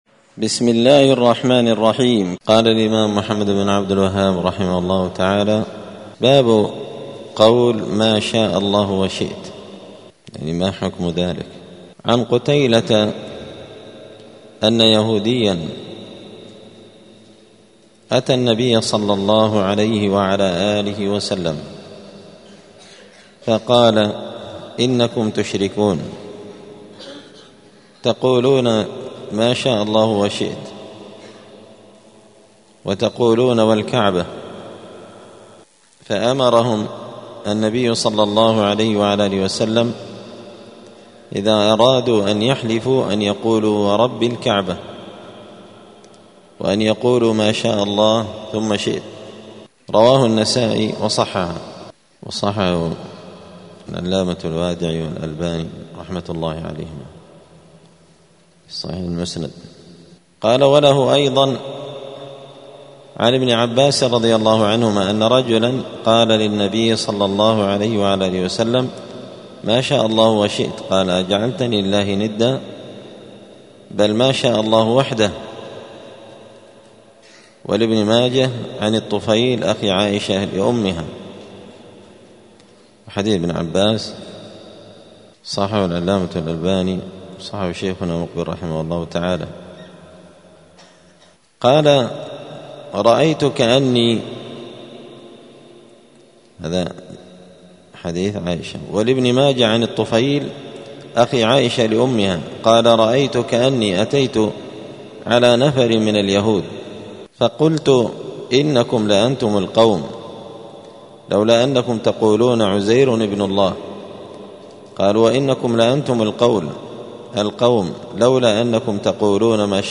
دار الحديث السلفية بمسجد الفرقان قشن المهرة اليمن
*الدرس الثاني والعشرون بعد المائة (122) {باب قول ماشاء الله وشئت}*